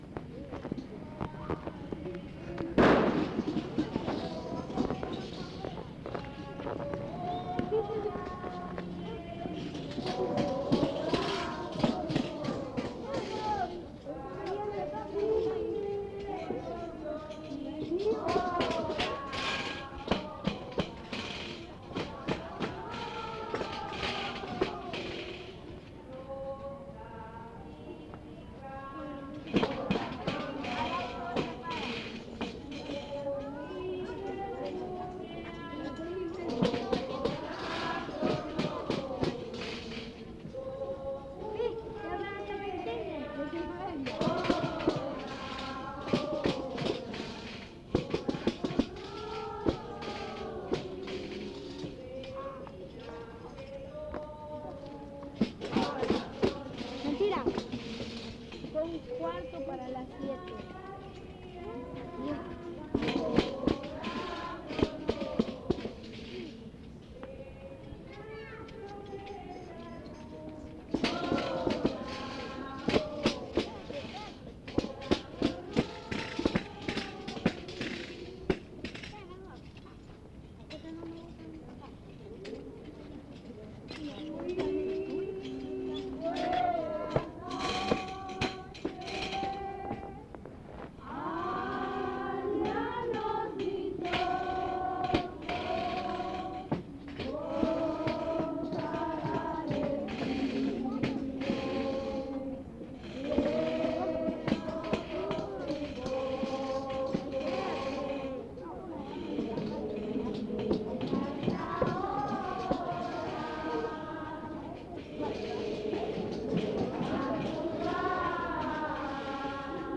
Entrevista
Tlacotalpan, Veracruz, Mexico